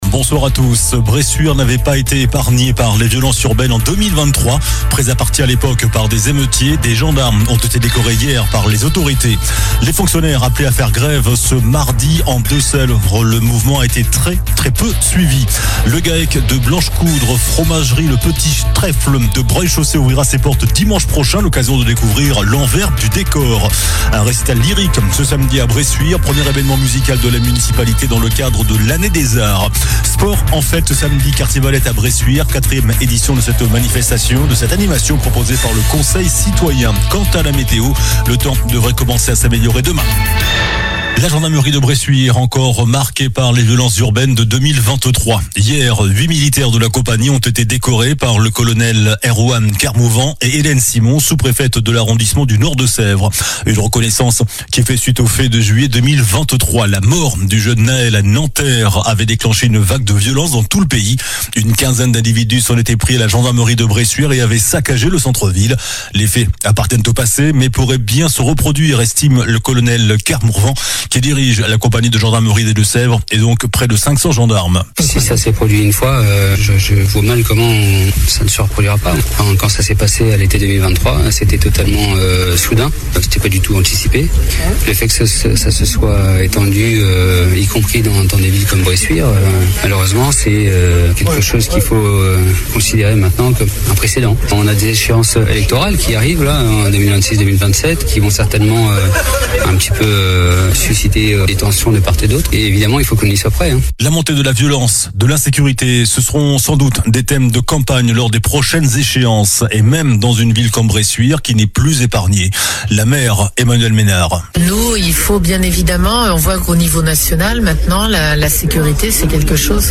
Journal du mardi 13 mai (soir)